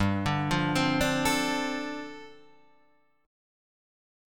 G7sus2sus4 chord